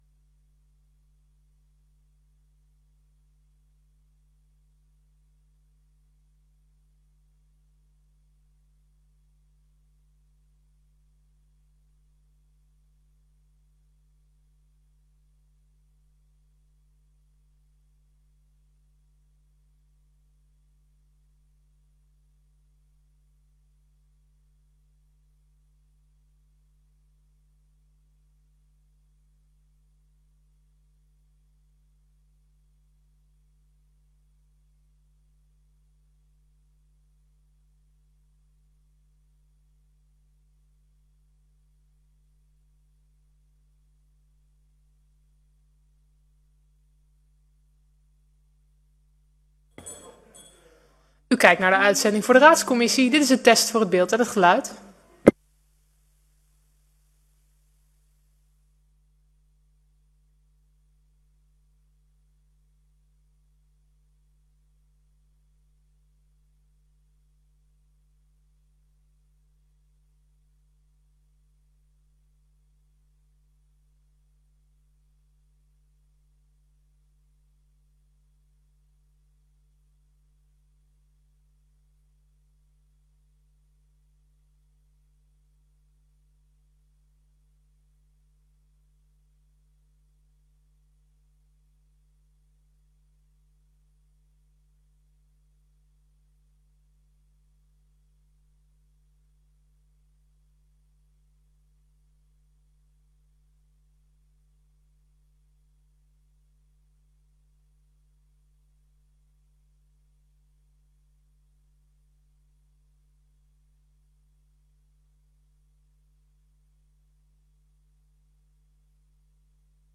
Raadscommissie 01 september 2025 19:30:00, Gemeente Dalfsen
Download de volledige audio van deze vergadering